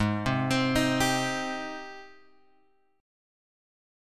G#M7 chord {4 3 5 5 x 3} chord